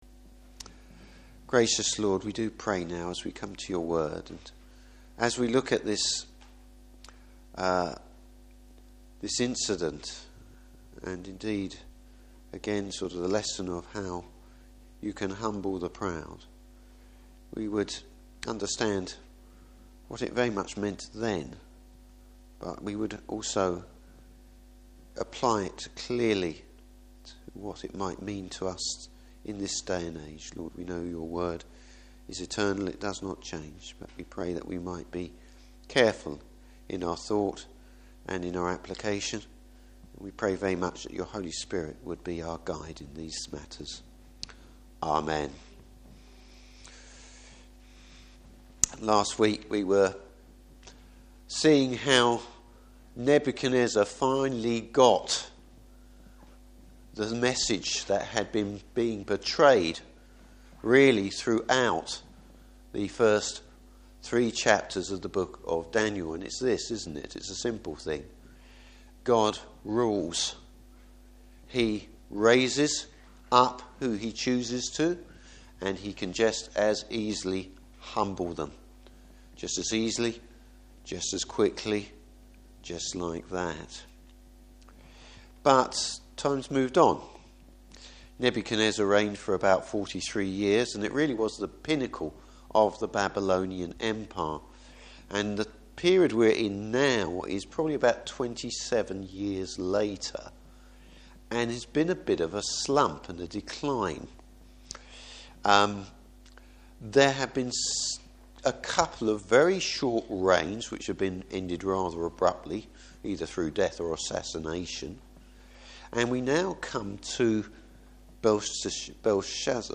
Service Type: Evening Service How King Belshazzar should have known better!